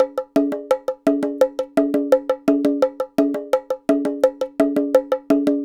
Bongo 17.wav